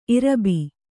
♪ irabi